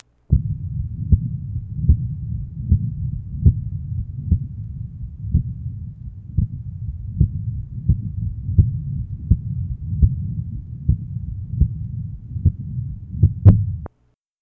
Date 1971 Type Systolic Abnormality Innocent murmur Interesting murmur over pulmonary artery area not thought to be ductus or venous hum To listen, click on the link below.